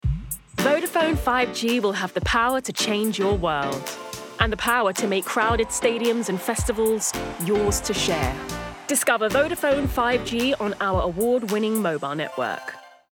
20/30s Neutral/London,
Confident/Engaging/Soothing